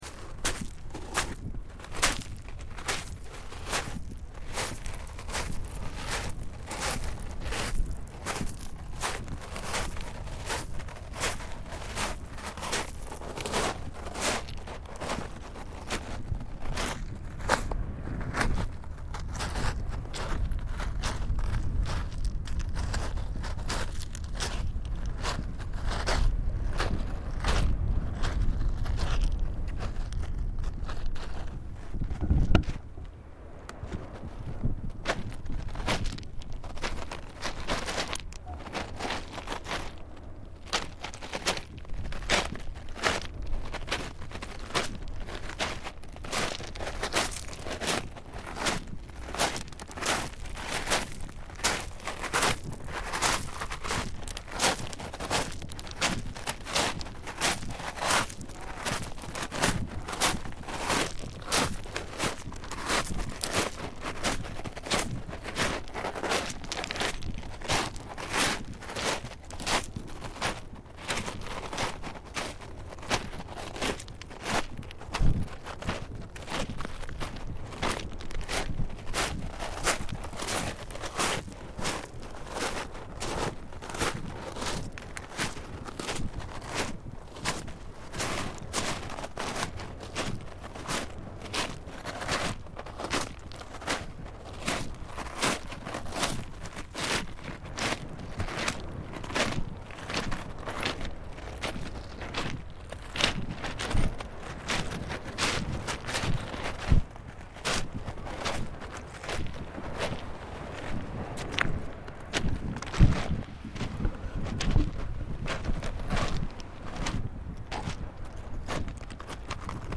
Walking on a volcanic beach
Walking on a stone/sand mixed beach, on which the sand is coloured black due to volcanic activity, on the island of Stromboli, which boasts an active volcano as its centrepiece.